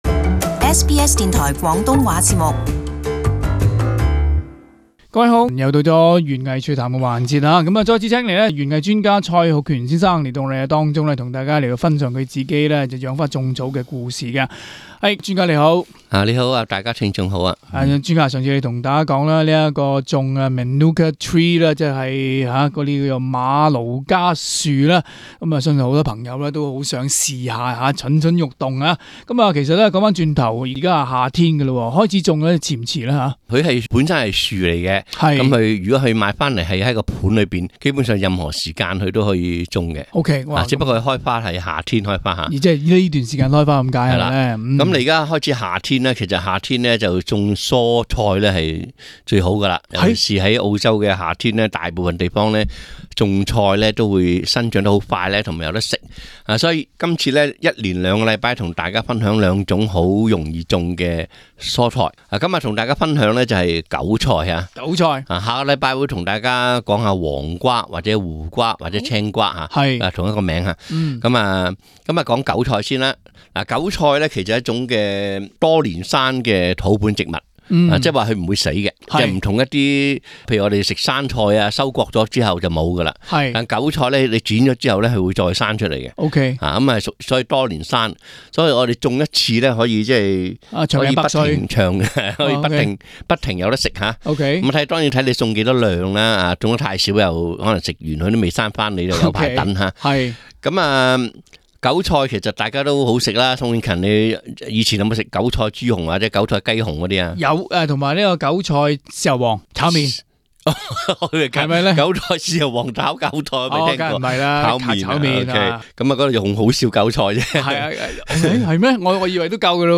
Pixabay Source: Pixabay SBS广东话播客 View Podcast Series Follow and Subscribe Apple Podcasts YouTube Spotify Download (23.21MB) Download the SBS Audio app Available on iOS and Android 相信很多喜欢吃银牙或大豆芽菜的朋友，也喜爱韭菜。